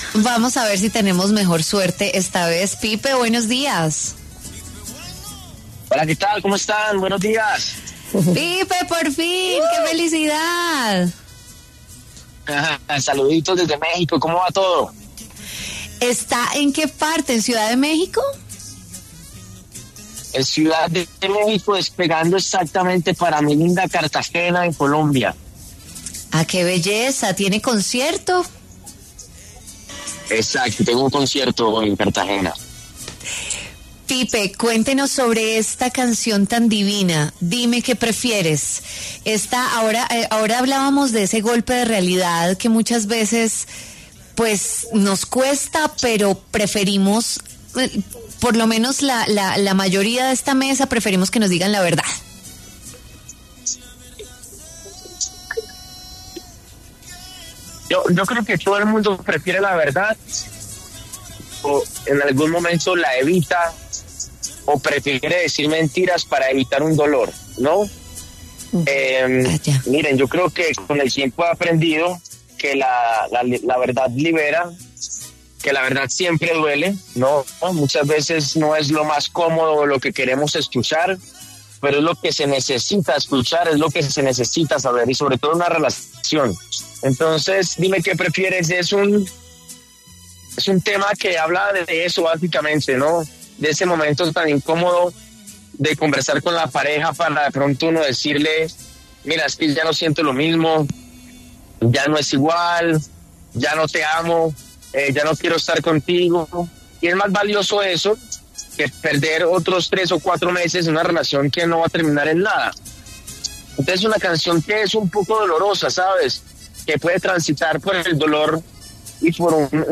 El cantante colombiano Pipe Bueno habló en W Fin de Semana sobre su nueva canción ‘Dime Qué Prefieres’.